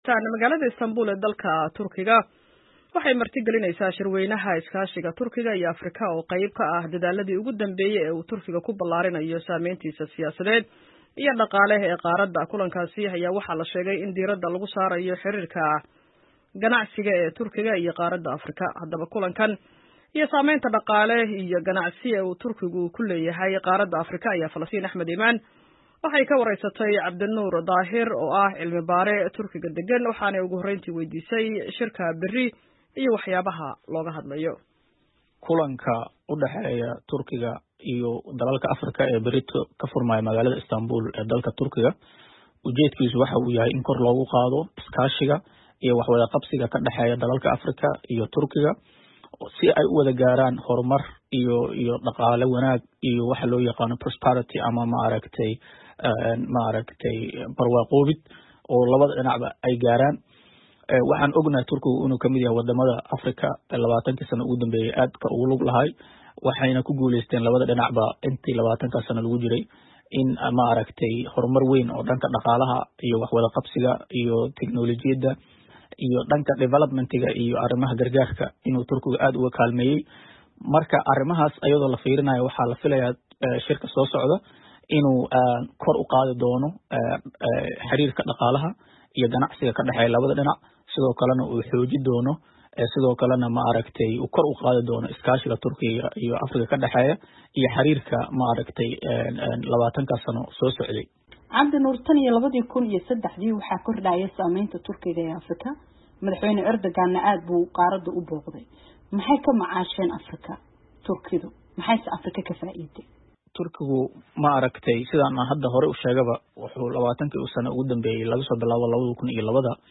Wareysi: Maxaa looga hadlayaa shirka Turkiga iyo Africa?